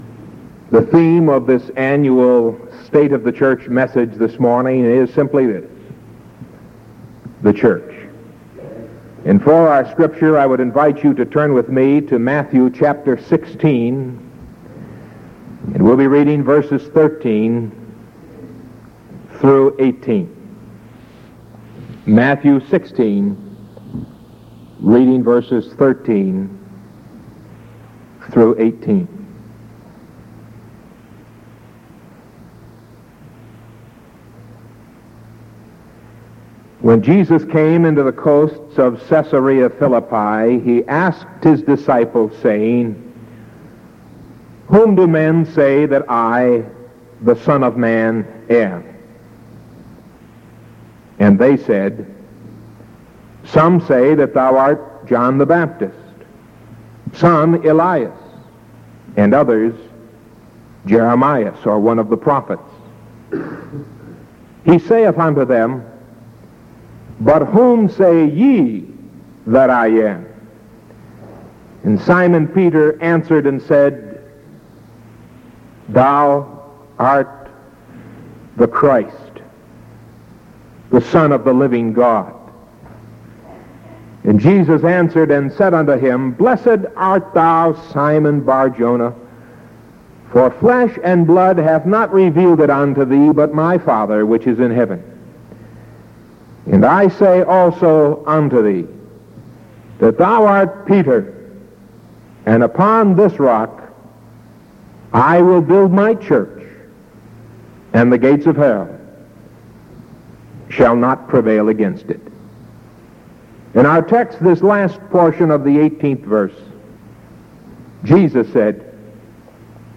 Sermon from June 24th 1973 AM